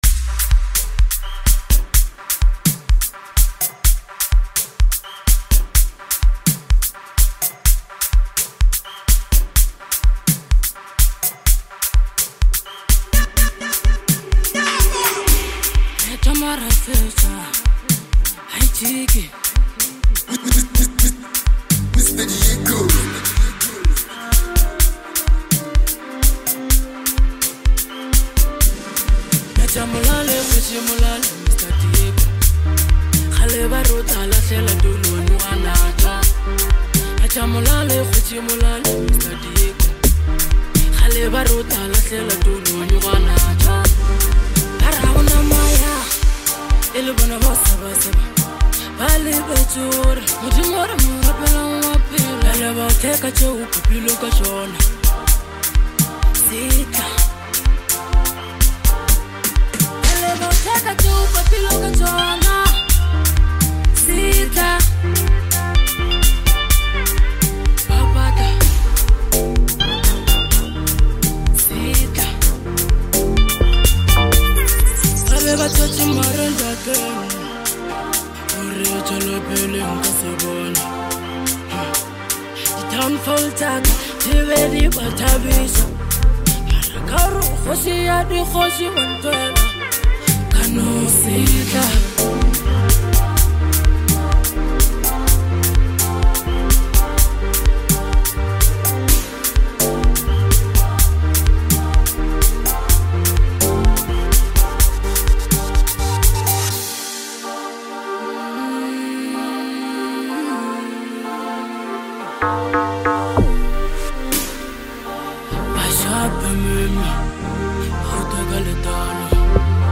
is a high energy track